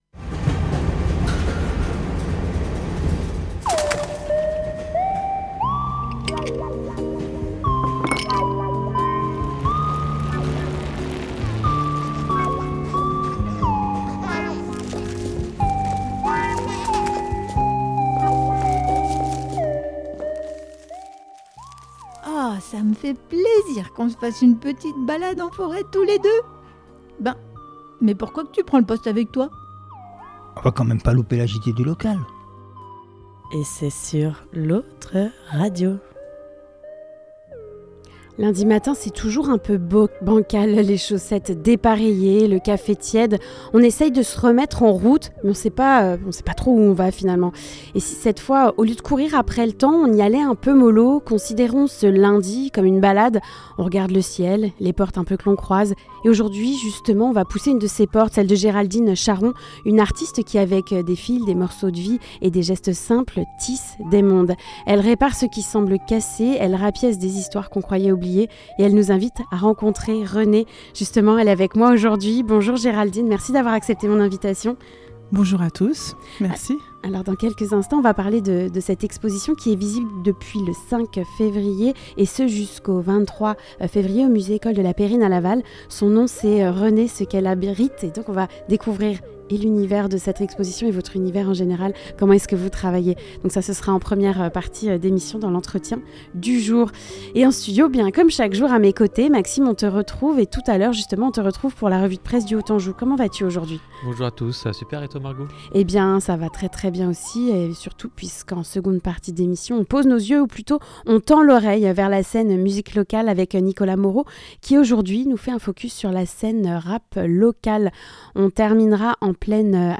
La revue de presse du Haut Anjou